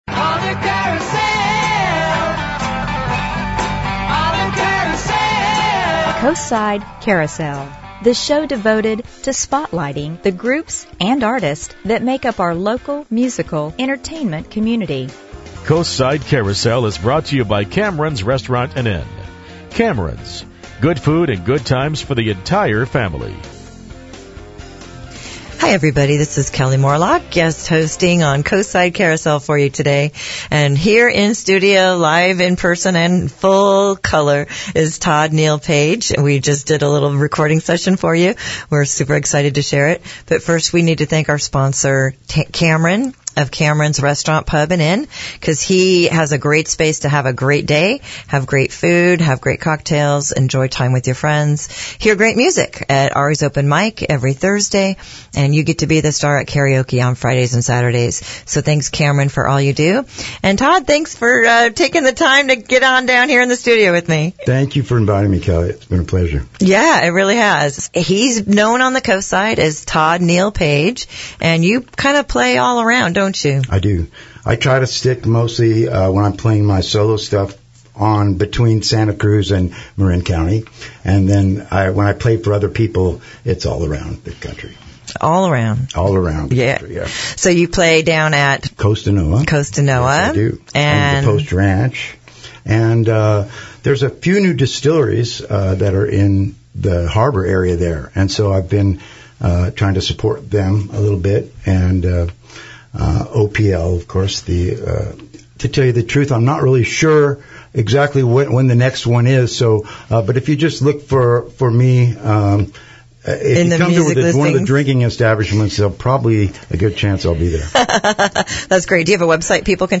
On the radio at AM 1710 and over the internet, KHMB Radio serves all the neighborhoods of Half Moon Bay with news, entertainment and information.